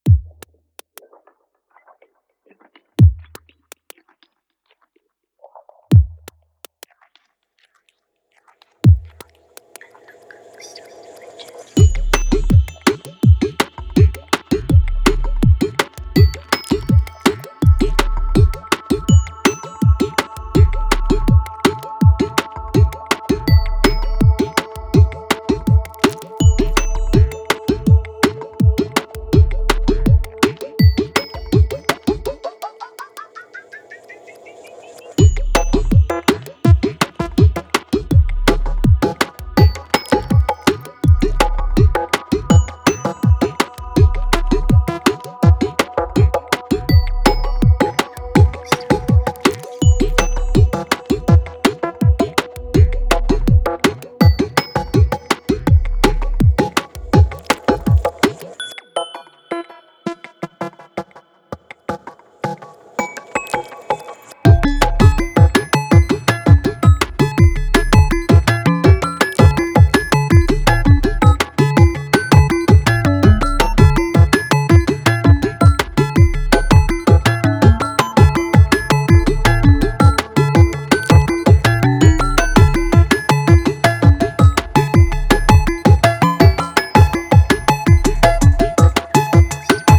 into a jumpy interplay of rhythms and joy
Techno